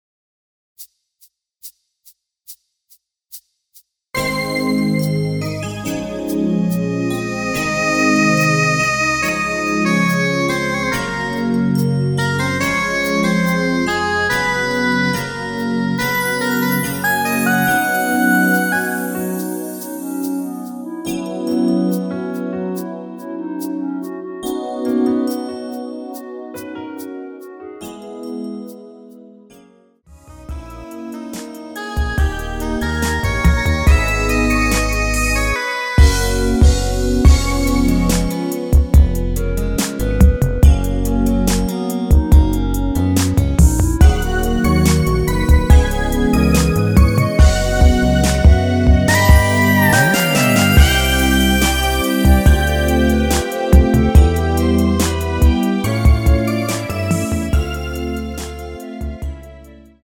원키에서(-2)내린 2절부분 삭제한 멜로디 포함된 MR입니다.
◈ 곡명 옆 (-1)은 반음 내림, (+1)은 반음 올림 입니다.
앞부분30초, 뒷부분30초씩 편집해서 올려 드리고 있습니다.